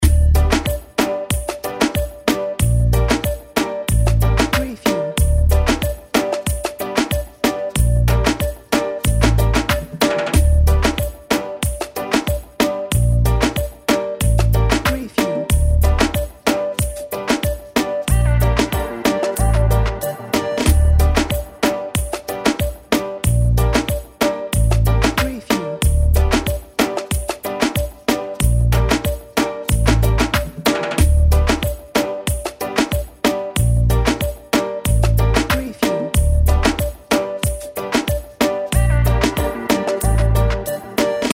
Tropical Loop - Editing Sounds
Warm summer music loop with a caribbean reggae feeling. Crafted for transitions, intro’s and endings.
tropical-loop